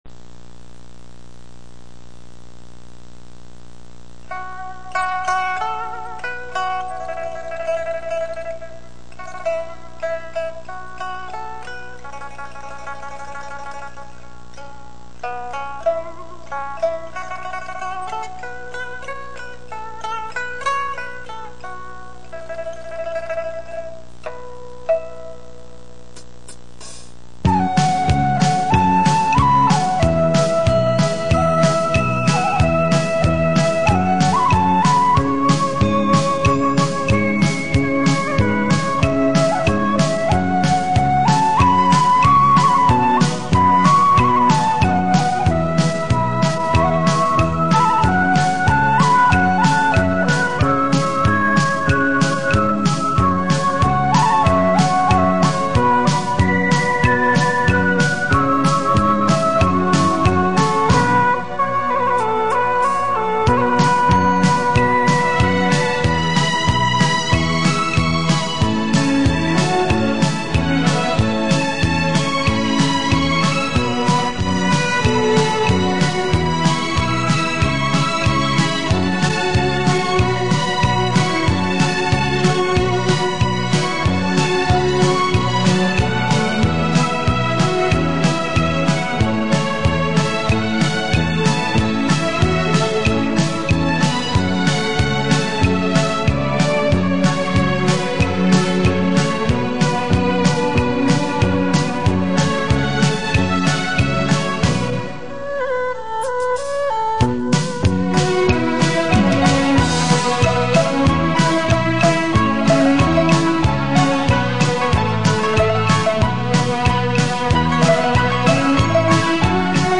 [22/11/2008]舞曲版 《春江花月夜》你绝对没听过！（磁带翻录，音质差点）